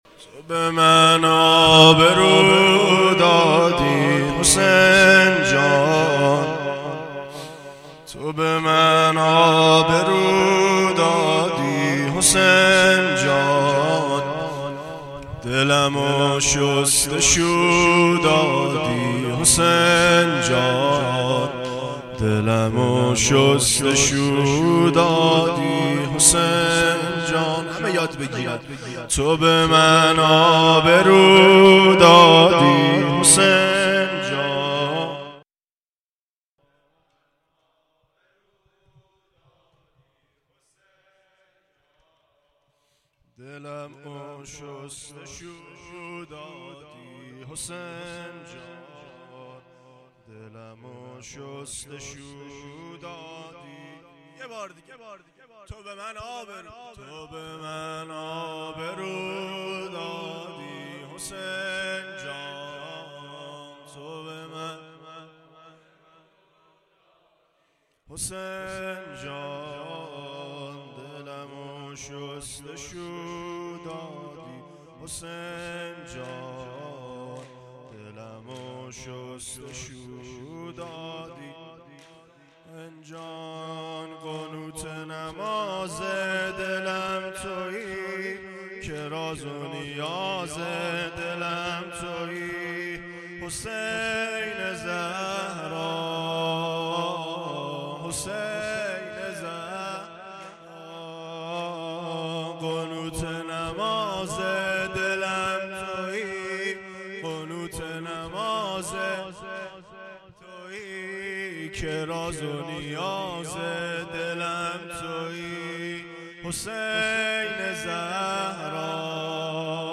زمینه شب پنجم رمضان
زمینه.mp3